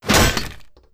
Melee Weapon Attack 9.wav